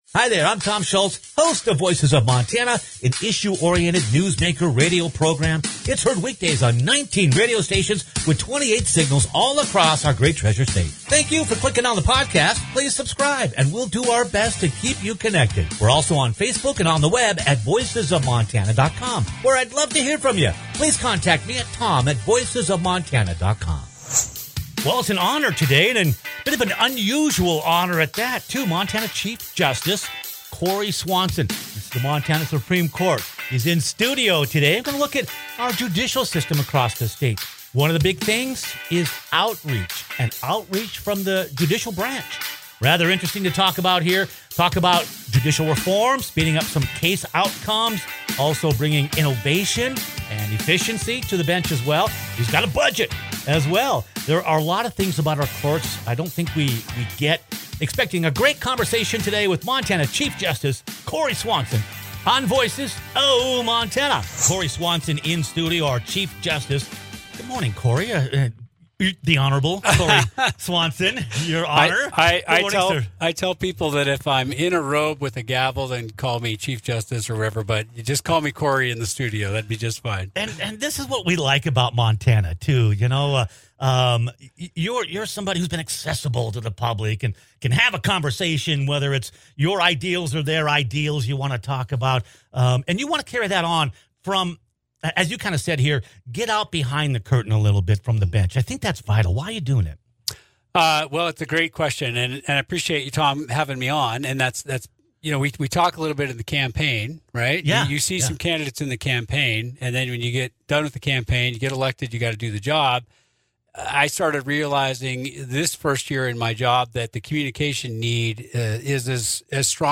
It was indeed an honor to have the Honorable Cory Swanson, Chief Justice of the Montana Supreme Court, visit in-studio. The outreach represented one of Justice Swanson's tenets in judicial reform; a more public-facing court system.